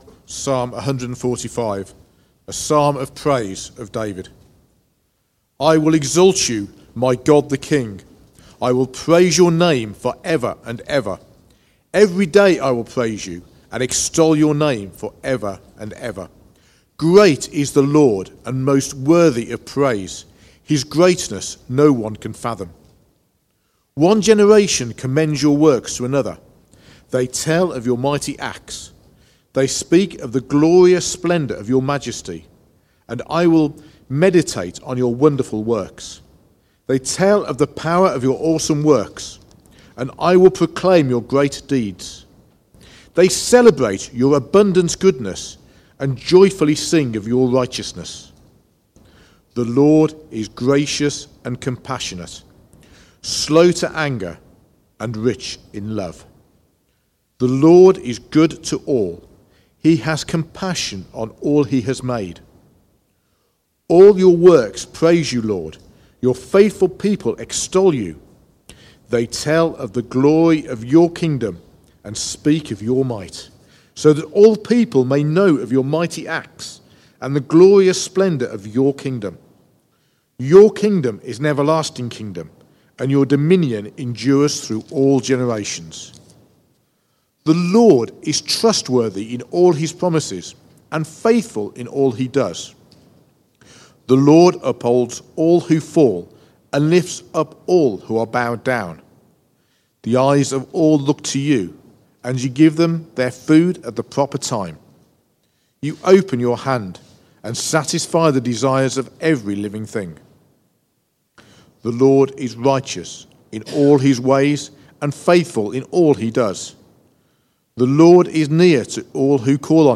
Preaching
Recorded at Woodstock Road Baptist Church on 04 January 2026.